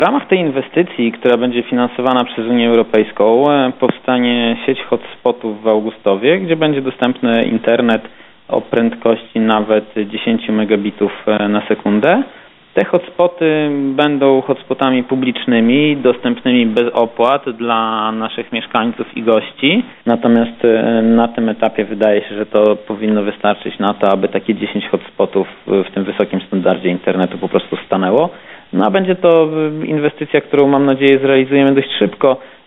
Dofinansowanie zostanie przeznaczone na zakup i instalację dziesięciu hotspotów w miejscach, najczęściej odwiedzanych przez mieszkańców i turystów w Augustowie. O szczegółach mówi Filip Chodkiewicz, zastępca burmistrza Augustowa.